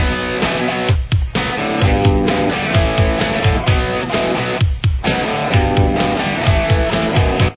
Guitar 1